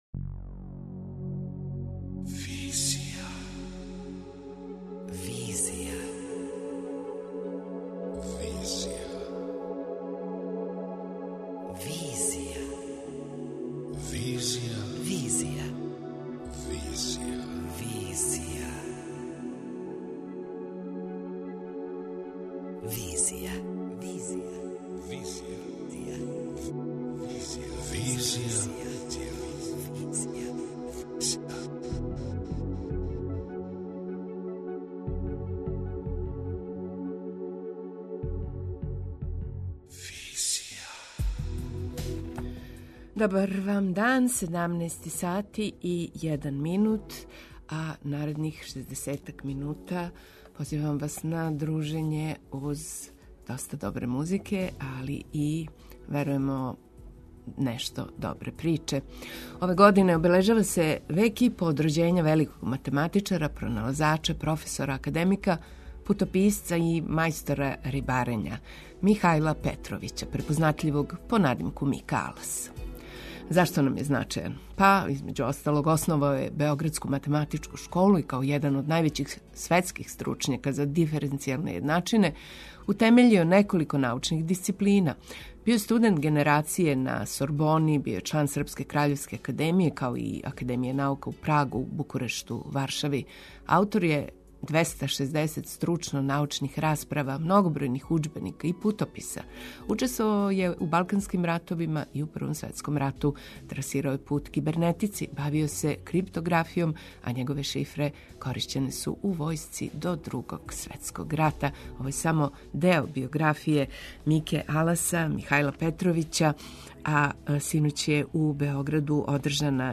преузми : 27.09 MB Визија Autor: Београд 202 Социо-културолошки магазин, који прати савремене друштвене феномене.